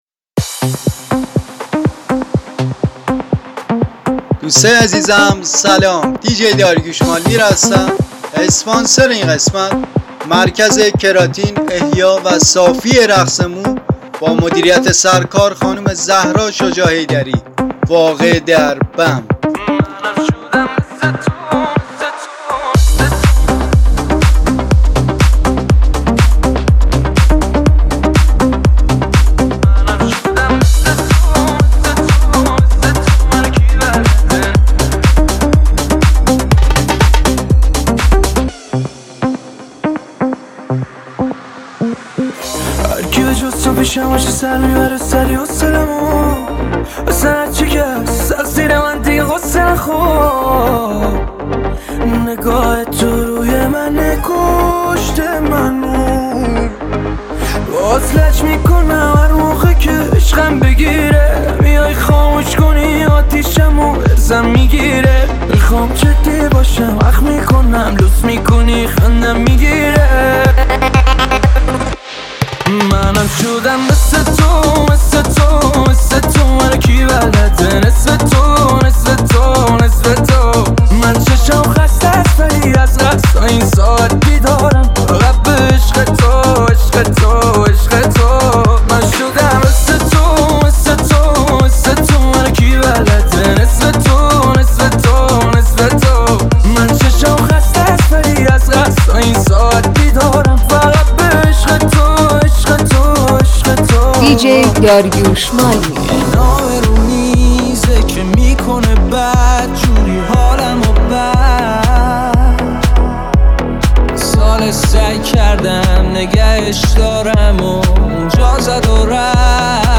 دانلود پادکست پرانرژی
ریمیکس